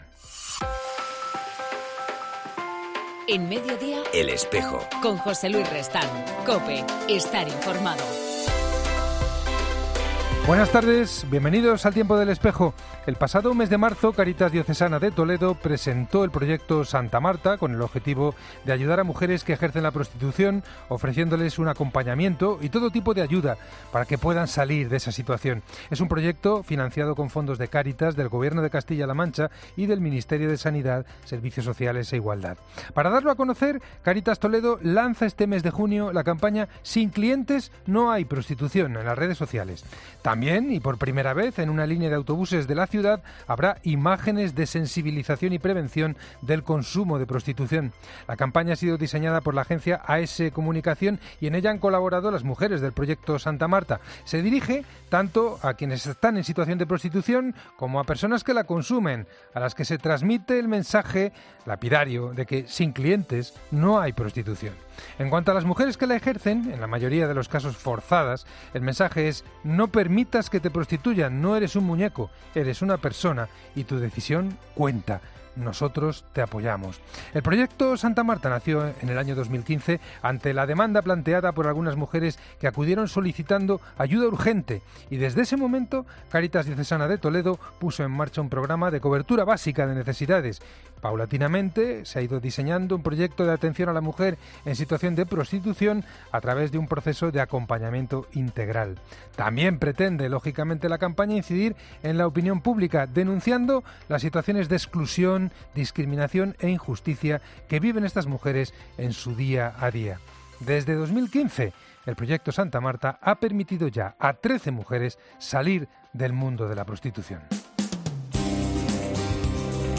En El Espejo del 14 de junio hablamos con